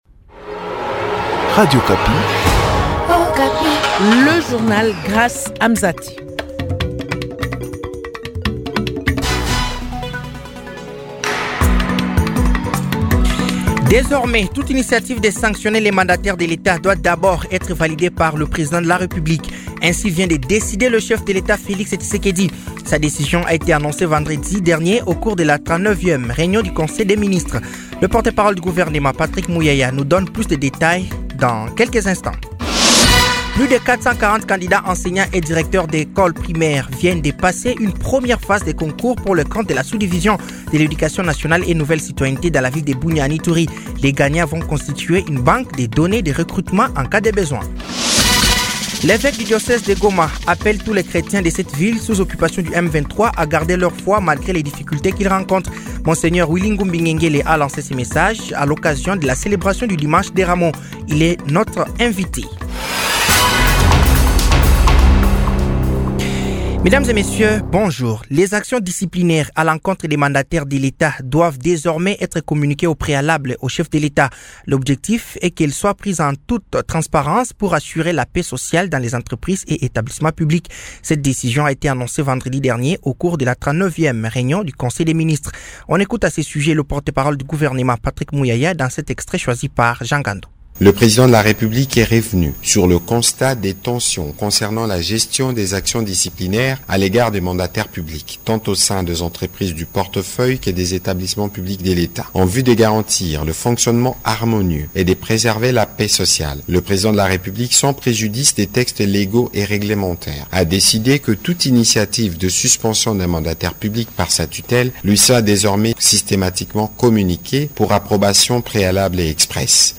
Journal français de 15h de ce lundi 14 avril 2025